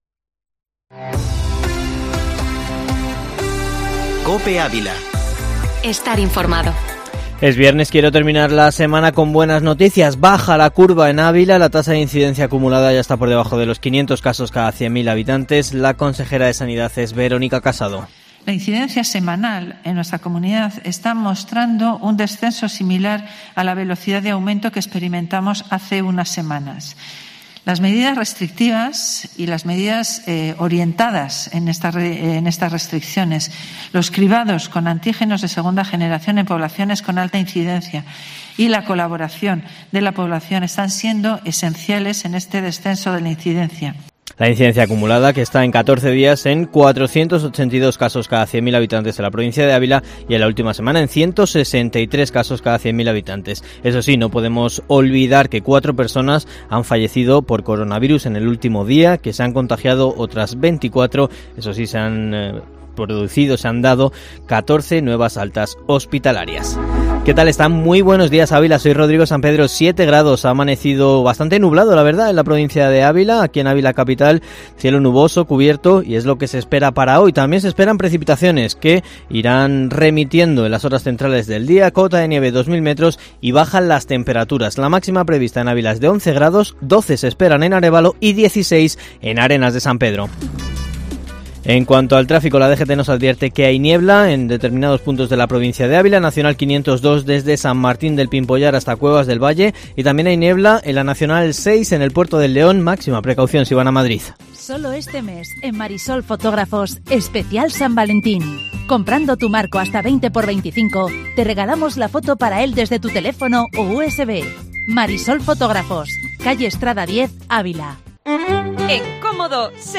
Informativo Matinal Herrera en COPE Ávila 12/02/2021